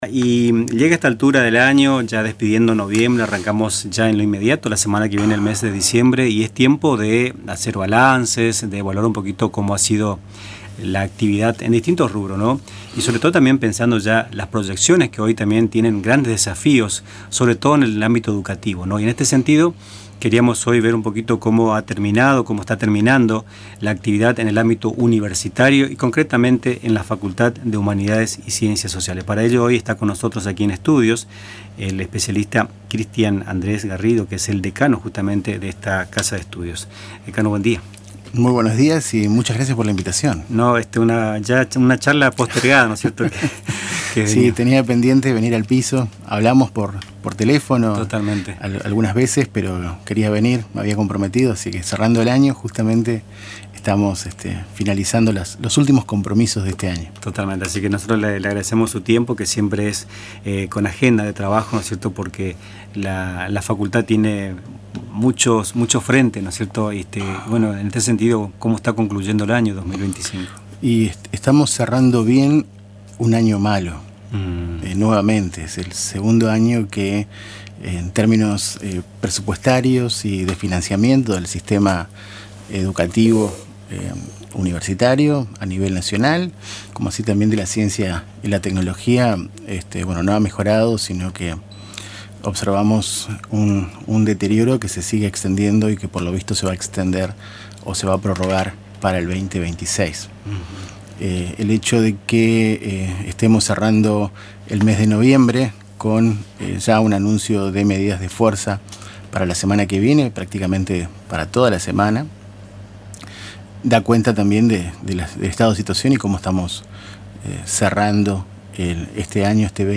Escuchá la entrevista completa en Radio Tupambaé